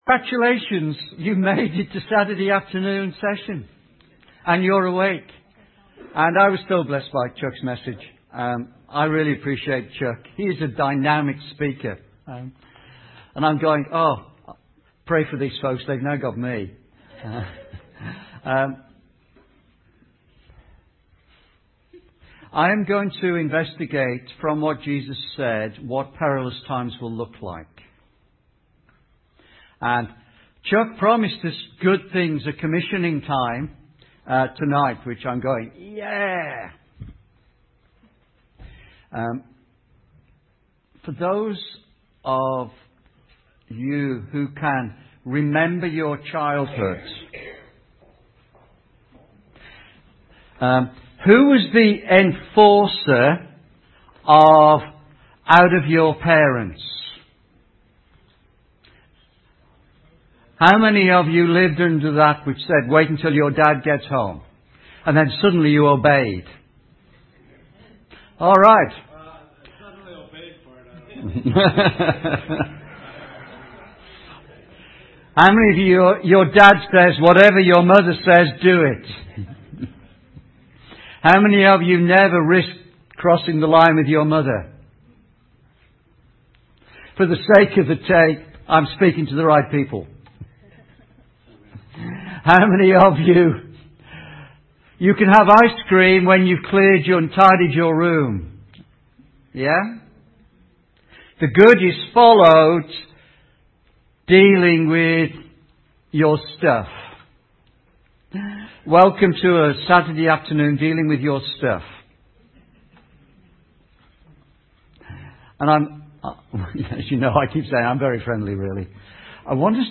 2015 Prophetic Conference | Mt Zion Church, Bemidji